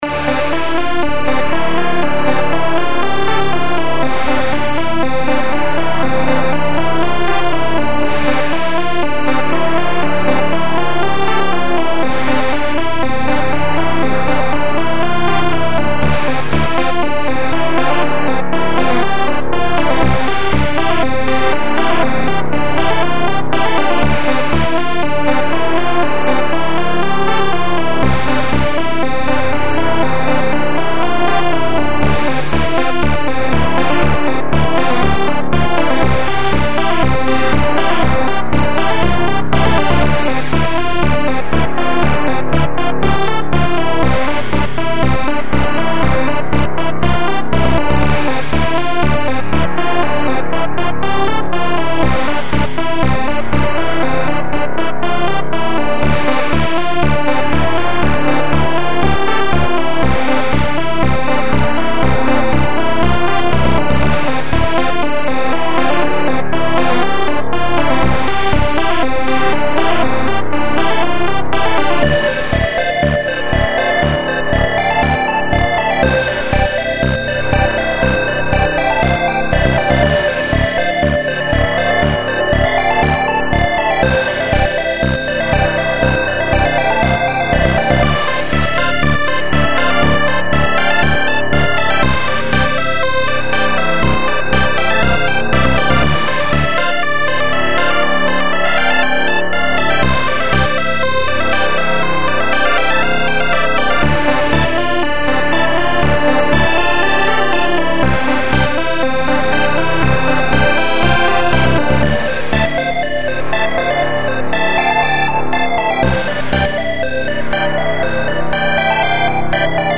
This song I wrote in Fruity Loops to sounds like a old Nintendo song from the first Nintendo. I enjoyed playing the old Nintendo so I figured I would make something that would have that same kind of sound.